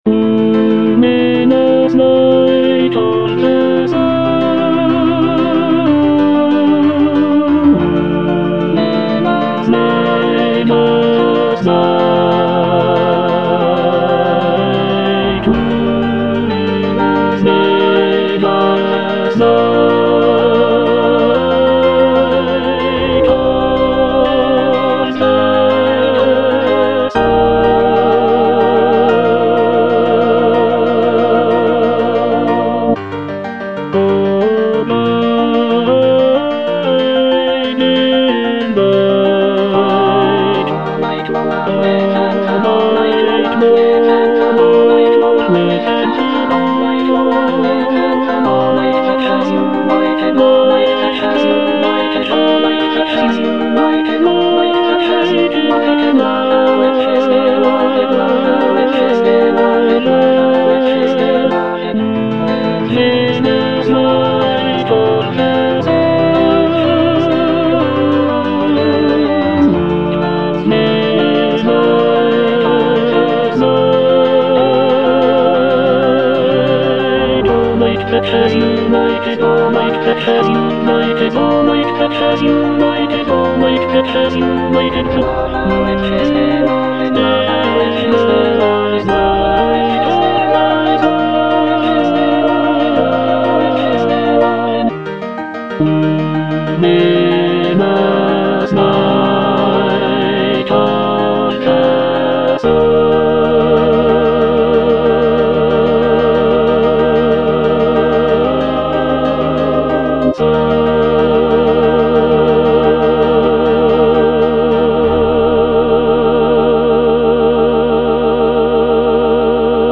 (tenor II) (Emphasised voice and other voices) Ads stop